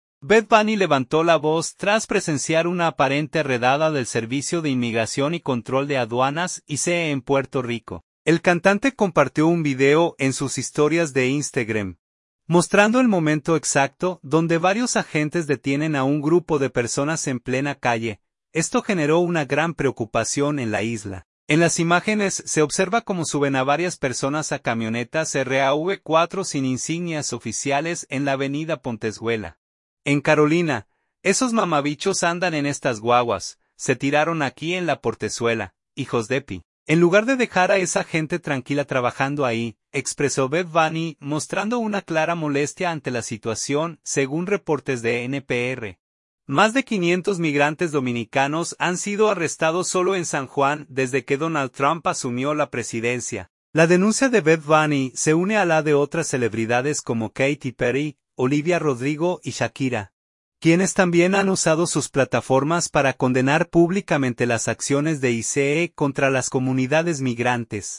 El cantante compartió un video en sus historias de Instagram, mostrando el momento exacto donde varios agentes detienen a un grupo de personas en plena calle.
En las imágenes se observa cómo suben a varias personas a camionetas RAV4 sin insignias oficiales en la Avenida Pontezuela, en Carolina.